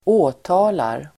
Uttal: [²'å:ta:lar]